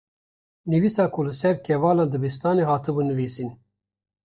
/nɪviːˈsiːn/